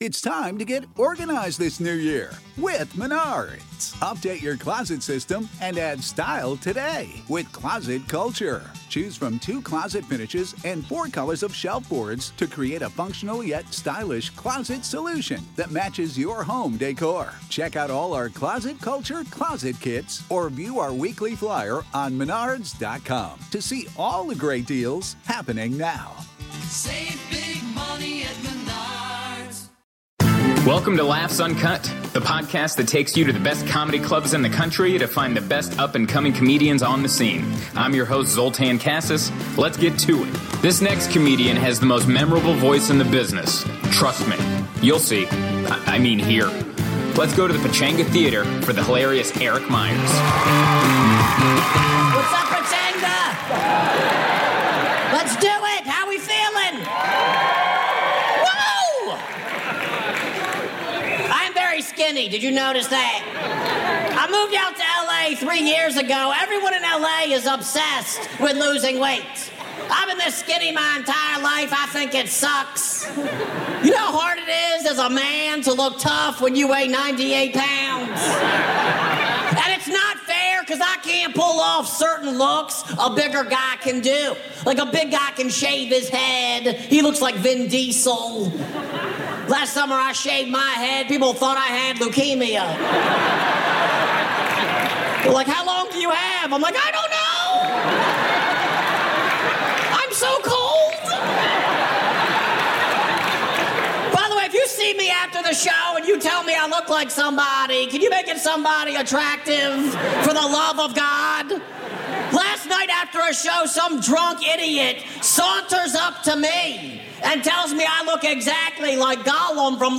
Comedians